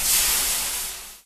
音效(未测试)
fizz.ogg